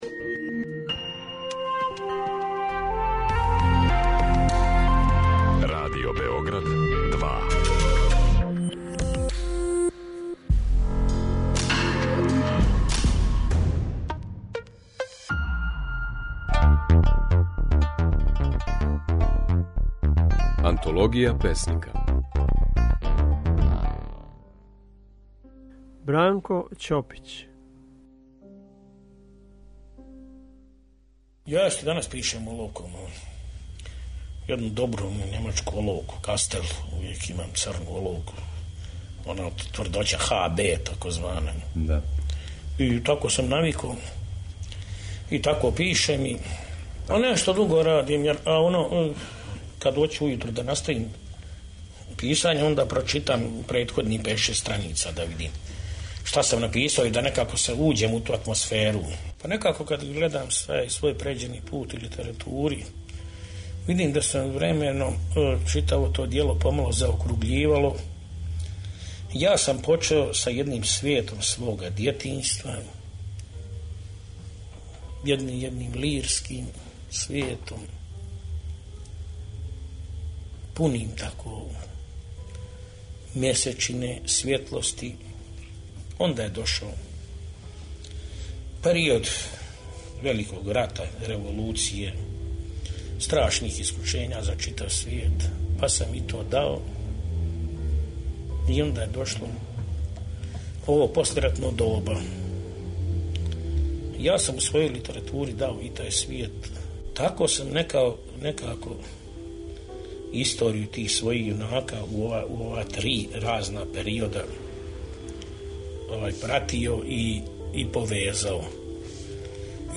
У емисији 'Антологија песника', можете чути како је своје стихове говорио наш познати песник и писац Бранко Ћопић (1915-1984).